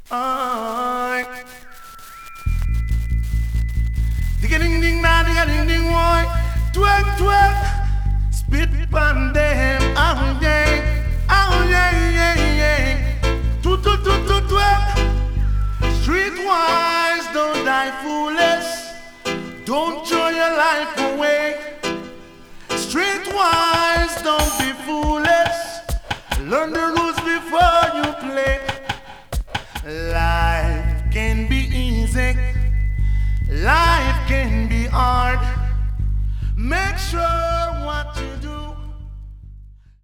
Genre: Reggae, Roots